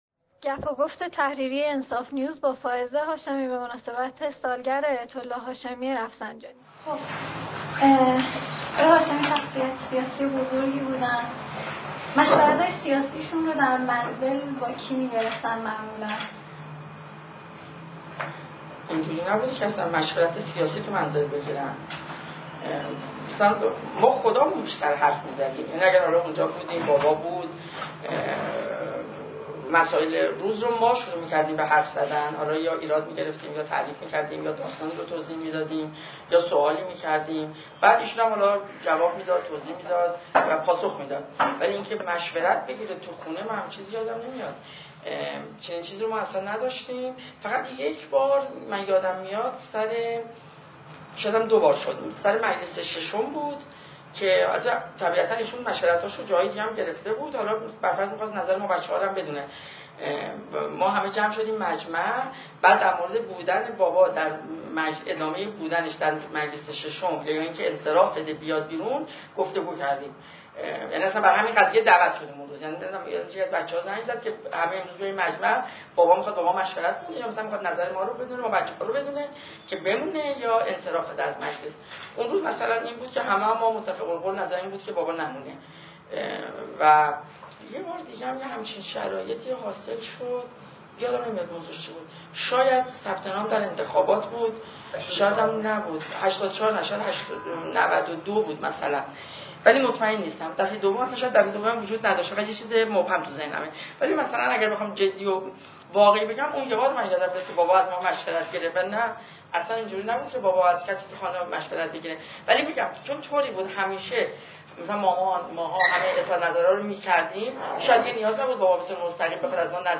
گپ و گفت با فائزه هاشمی در سالگرد آیت الله [صدا و عکس] | پایگاه خبری تحلیلی انصاف نیوز
فائزه هاشمی همزمان با دومین سالگرد درگذشت آیت الله هاشمی رفسنجانی، سه‌شنبه میهمان گعده هفتگی انصاف نیوز بود. او در این برنامه به پرسش‌های تعدادی از اعضای تحریریه و اعضای بخش تحلیلی این پایگاه خبری درباره‌ی سبک زندگی خصوصی پدر، همچنین برخی دیدگاه‌ها و رفتارهای سیاسی او در زمان ریاست جمهوری و پس از آن که برداشت‌های متفاوتی از آنها در جامعه وجود دارد، پاسخ داد.